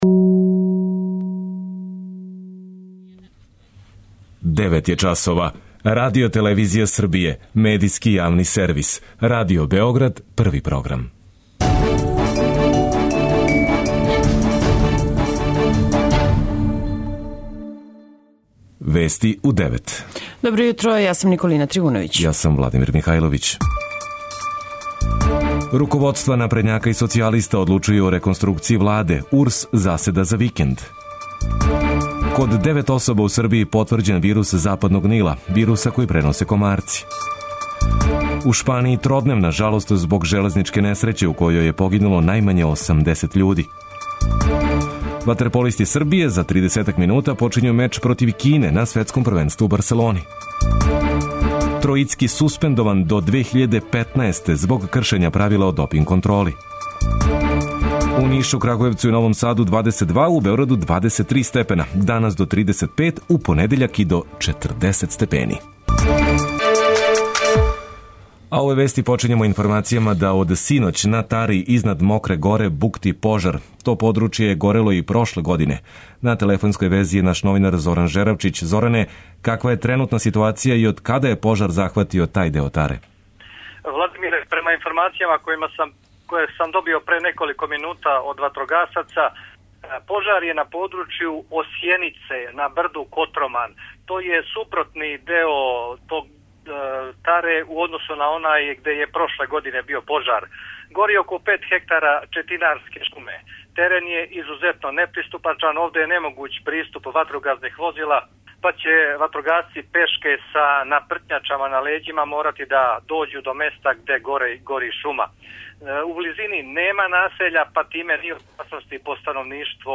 преузми : 10.49 MB Вести у 9 Autor: разни аутори Преглед најважнијиx информација из земље из света.